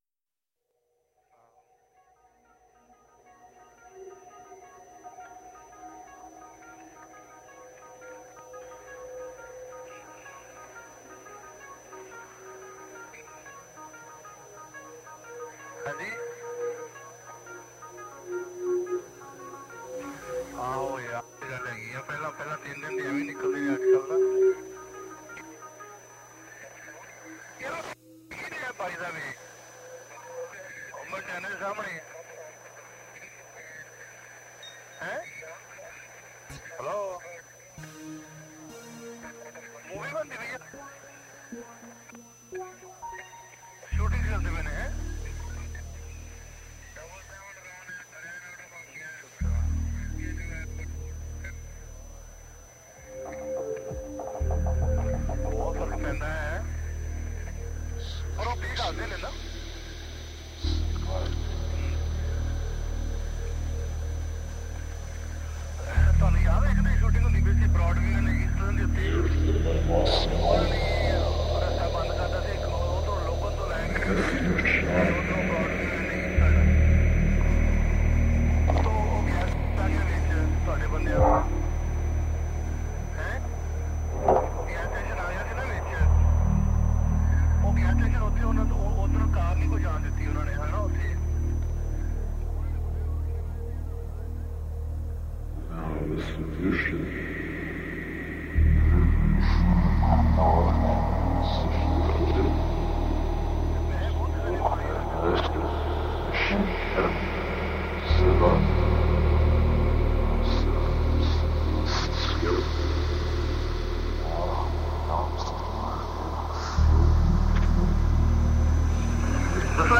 An office worker in Memphis, Tennessee plays music from his record collection, with a focus on post-punk, electronic music, dub, and disco.